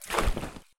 net_splash.mp3